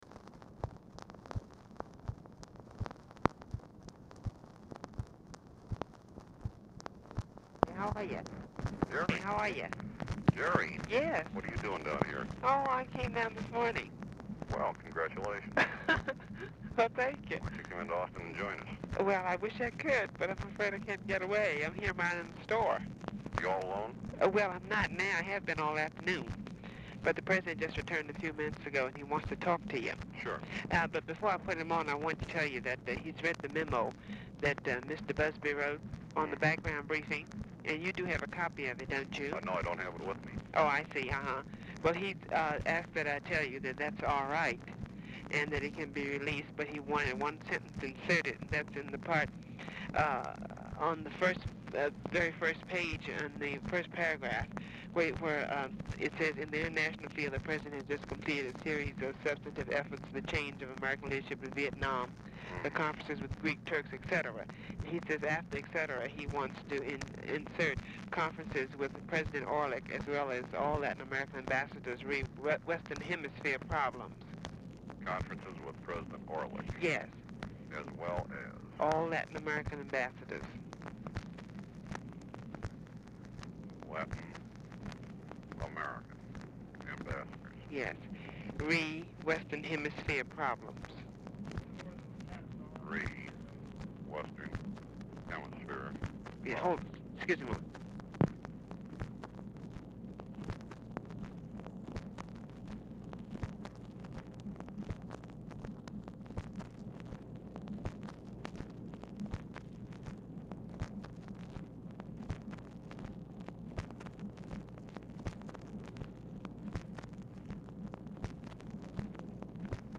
Telephone conversation # 4155, sound recording, LBJ and GEORGE REEDY, 7/4/1964, 7:25PM
Format Dictation belt
Location Of Speaker 1 LBJ Ranch, near Stonewall, Texas